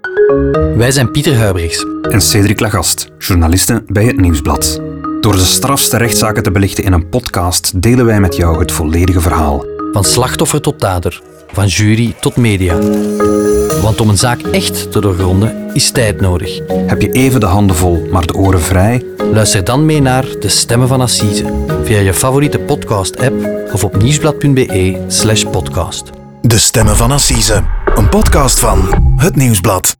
HetNieuwsblad_NL30_StemmenVanAssisen_Alt_Radio.wav